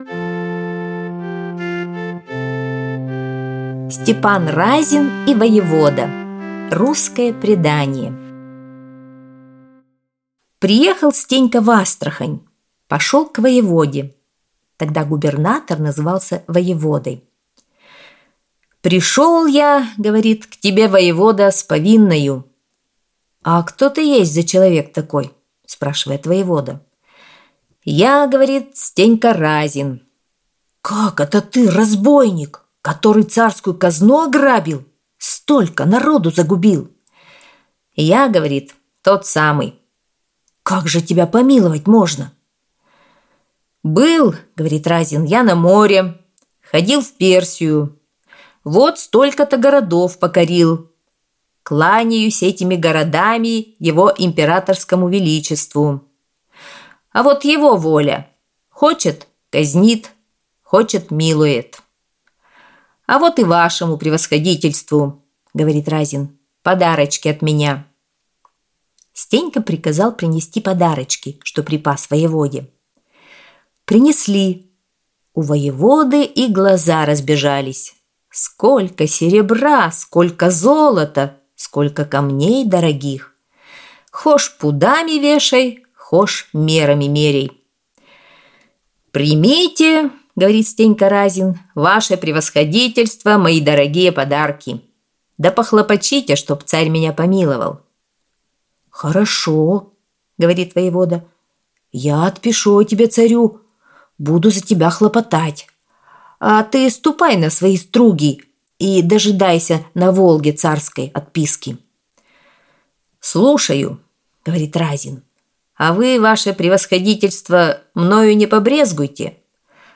Аудиосказка «Степан Разин и воевода»